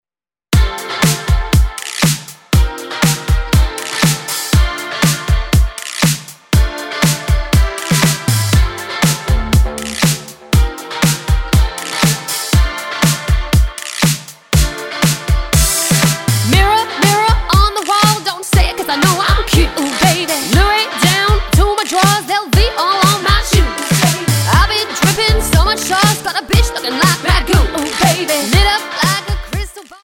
Tonart:Dm Multifile (kein Sofortdownload.
Die besten Playbacks Instrumentals und Karaoke Versionen .